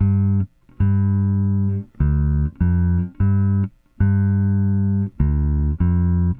Weathered Bass 01.wav